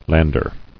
[land·er]